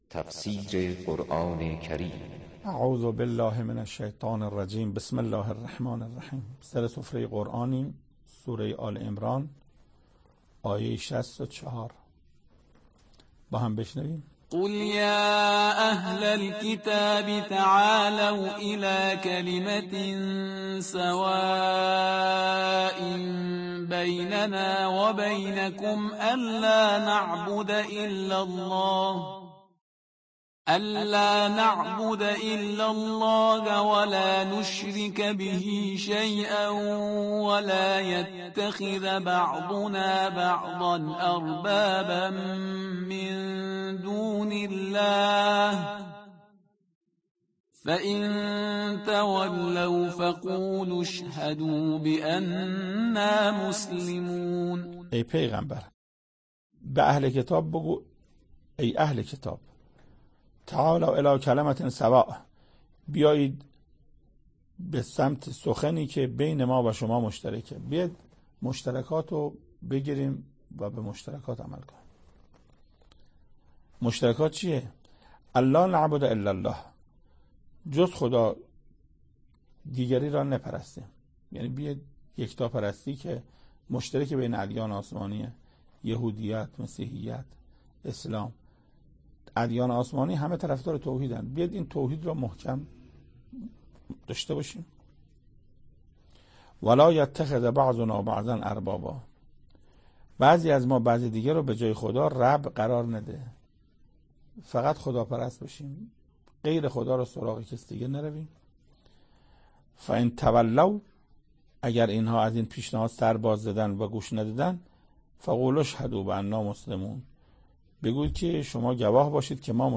سخنرانی محسن قرائتی